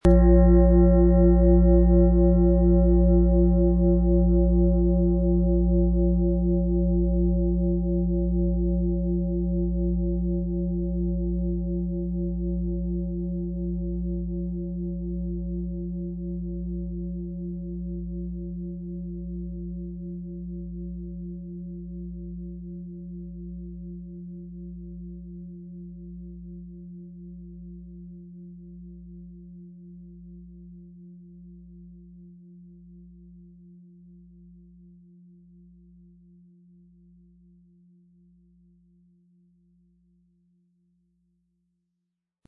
Fussreflexzonen-Schale mit Planetenton Wasser und Platonisches Jahr ca. 10,75 kg
Sie sehen eine Planetenklangschale Wasser, die in alter Tradition aus Bronze von Hand getrieben worden ist.
Wohltuende Klänge bekommen Sie aus dieser Schale, wenn Sie sie mit dem kostenlosen Klöppel sanft anspielen.
PlanetentonWasser & Platonisches Jahr (Höchster Ton)
MaterialBronze